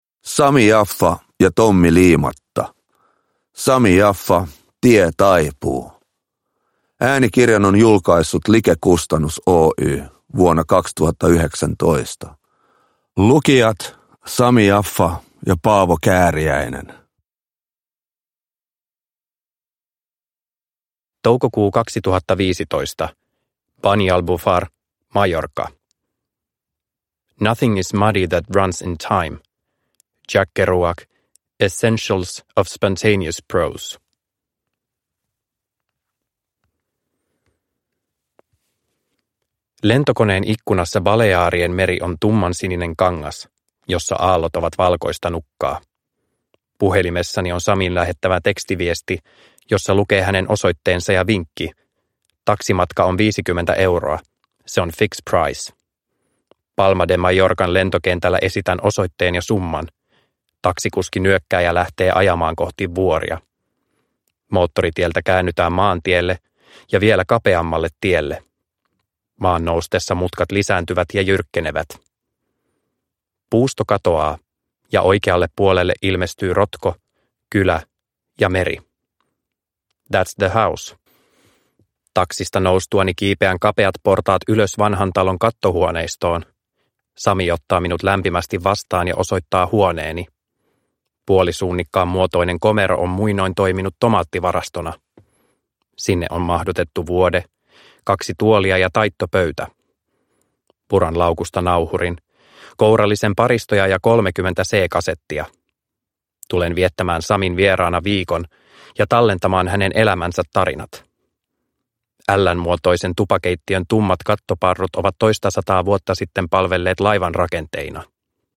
Sami Yaffa – Ljudbok – Laddas ner